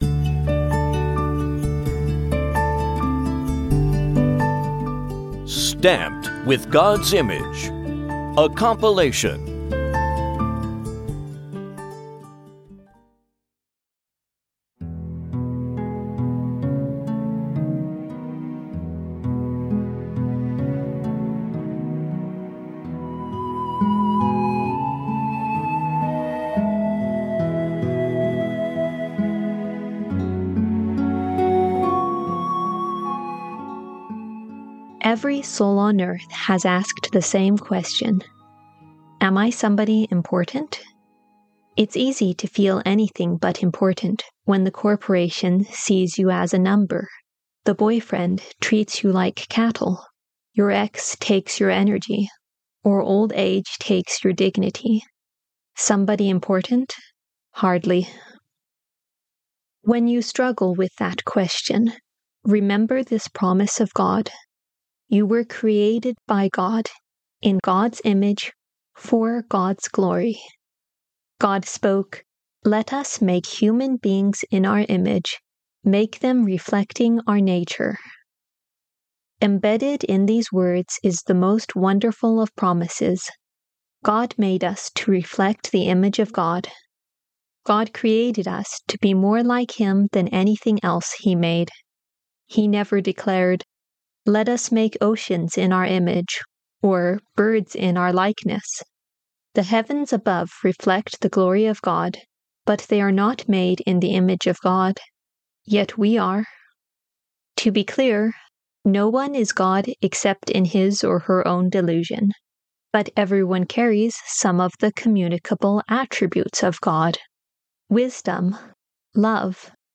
TFI_Devotional_Stamped_with_Gods_Image.mp3